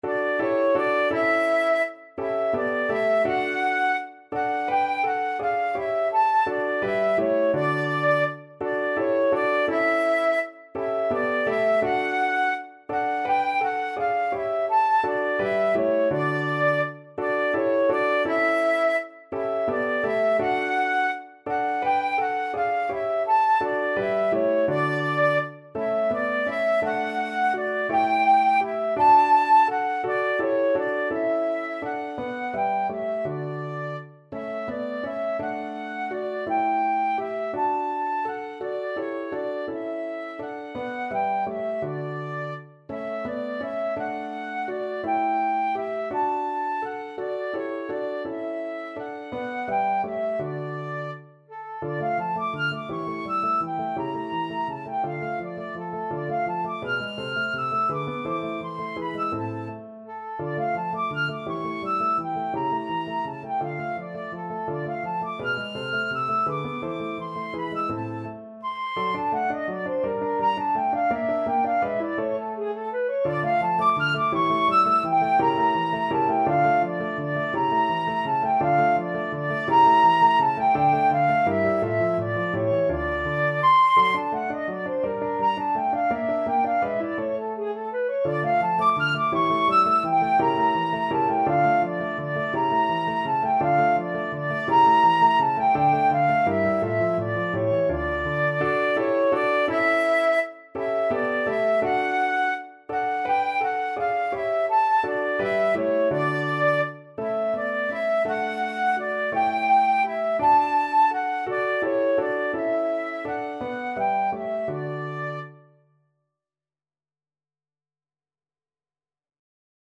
Flute
3/8 (View more 3/8 Music)
Lustig (Happy) .=56
Classical (View more Classical Flute Music)